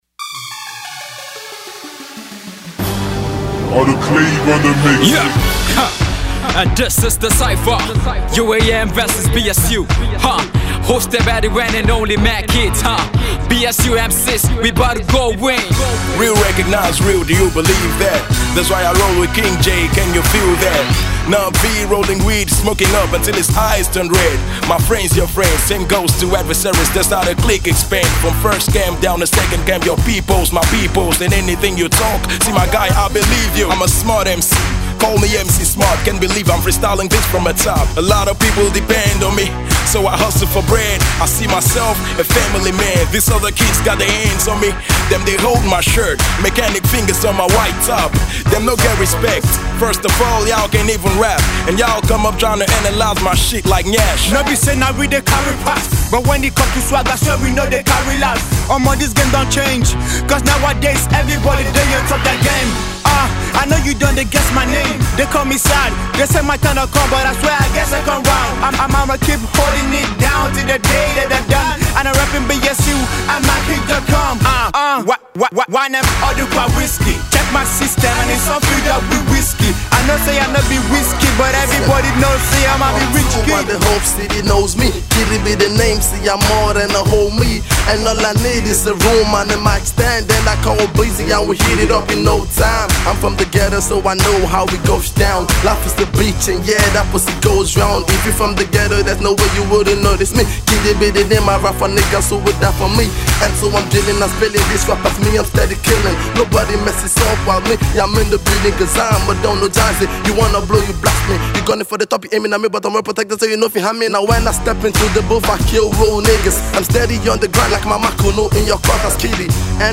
The song features deadly verses from 12 rappers